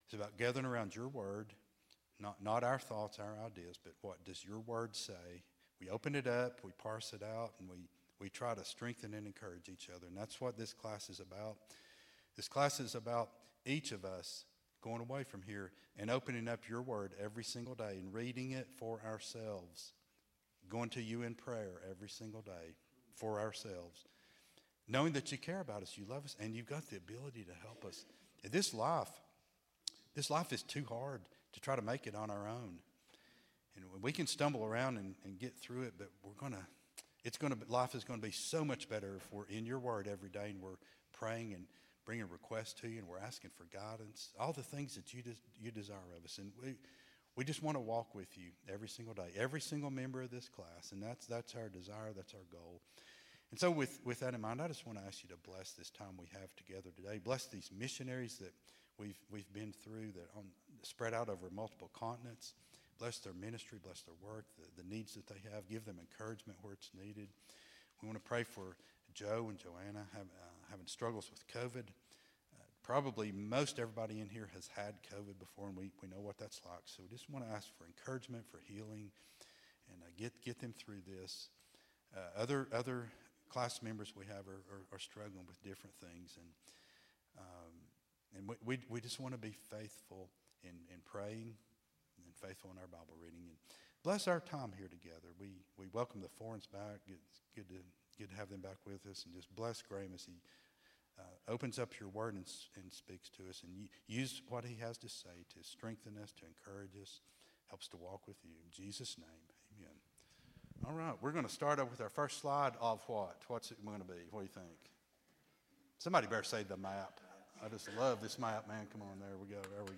Sunday School Lesson
at Buffalo Ridge Baptist Church in Gray, Tn.